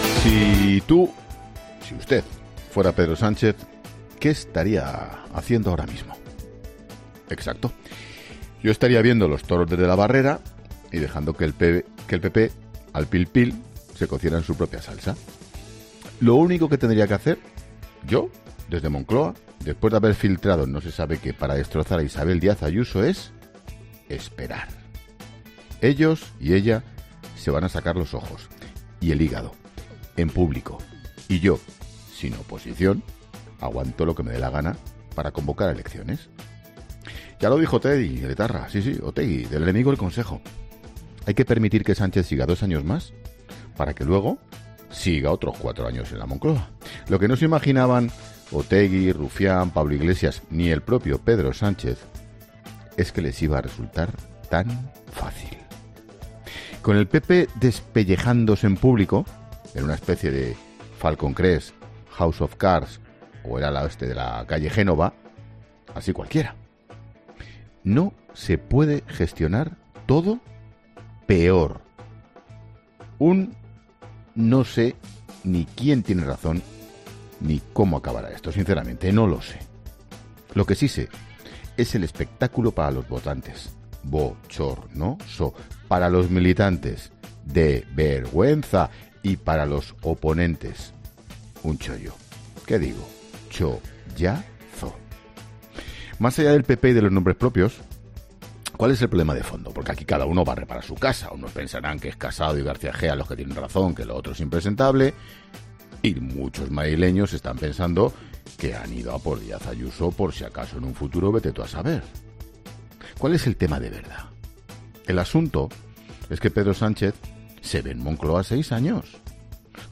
AUDIO: El director de 'La Linterna' habla en su monólogo de la grave crisis interna en el Partido Popular